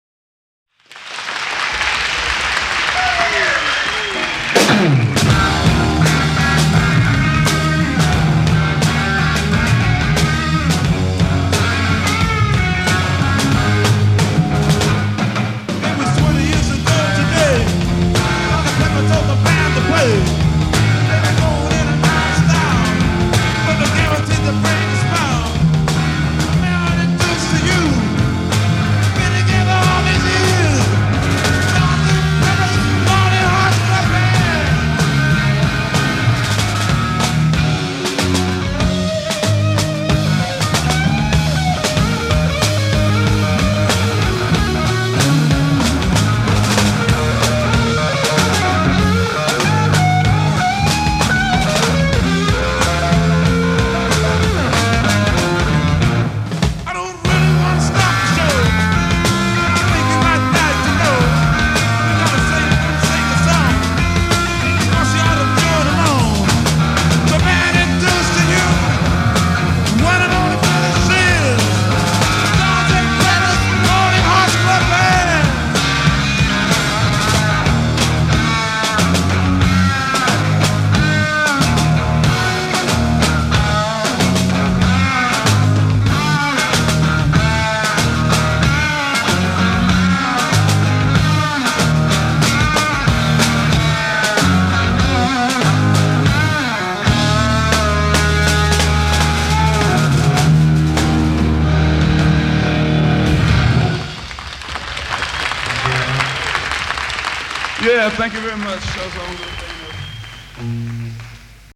slow and sludgy, loose and on the edge of falling apart
even playing the brass section as guitar riffs.
(Saville Theatre, London, 4.6.67)